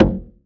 edm-perc-31.wav